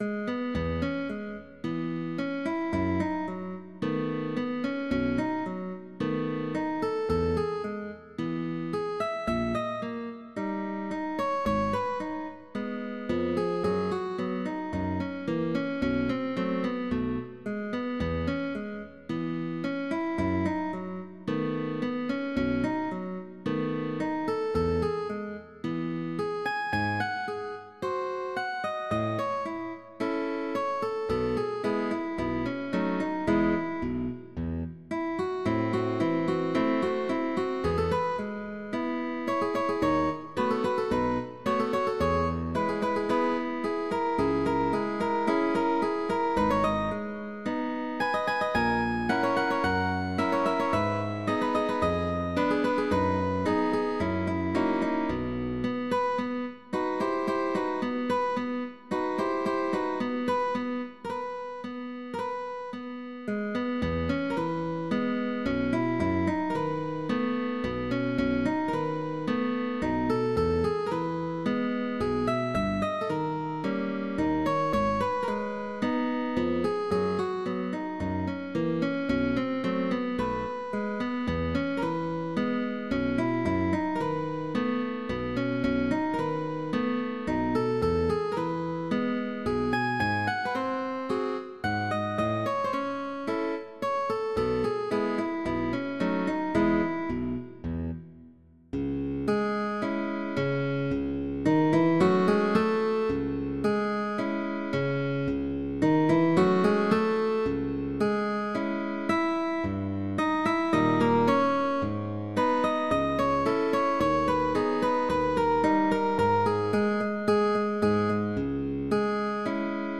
TRIO de GUITARRAS